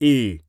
Male_Grunt_Hit_Neutral_02.wav